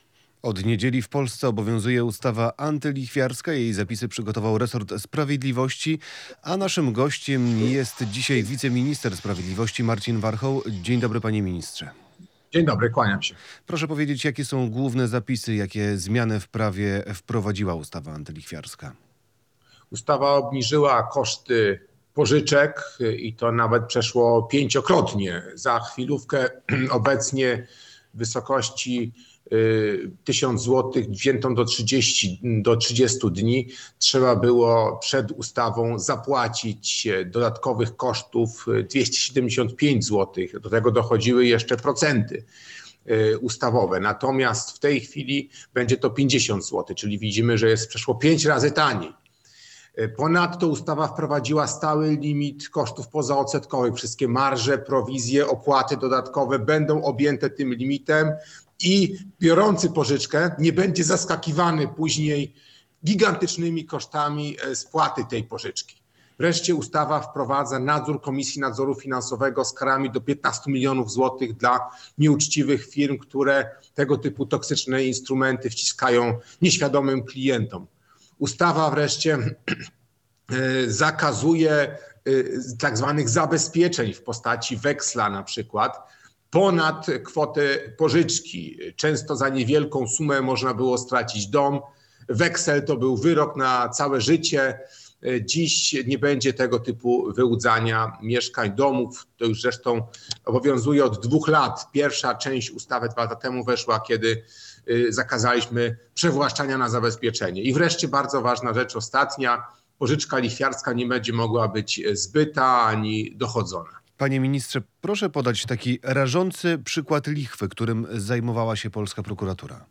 Radio Białystok | Gość | Marcin Warchoł [wideo] - wiceminister sprawiedliwości